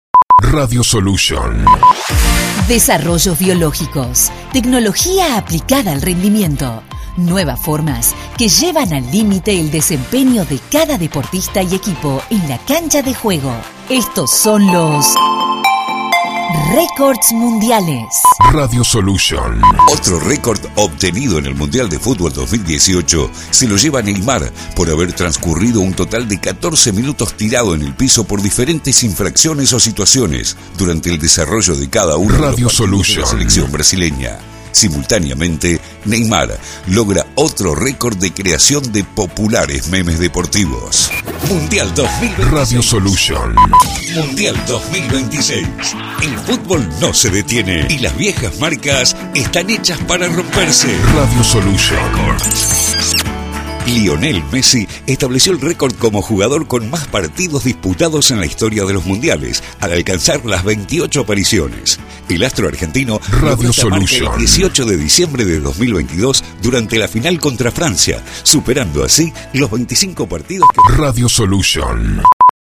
Con música y Efectos
Producción confeccionada a 1 voz